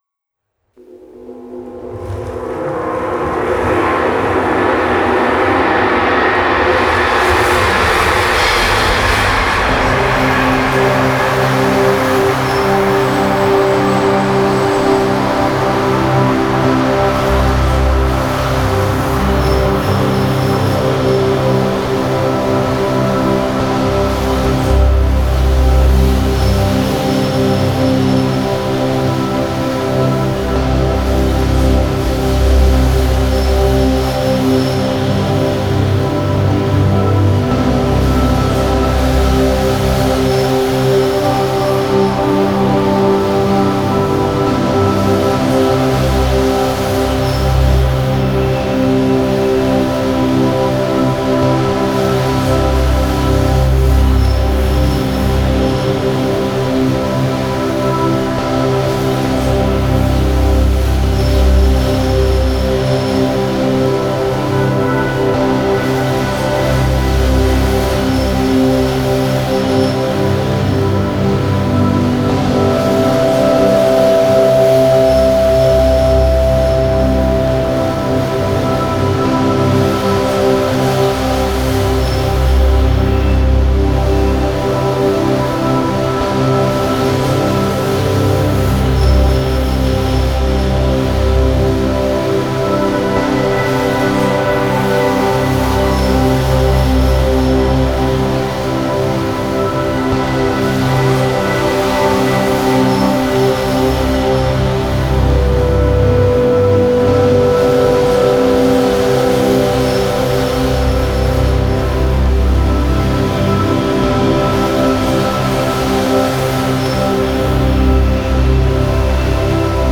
défricheur de musique mélangeant world music et sons electro
captations musicales et de magnifiques titres ambient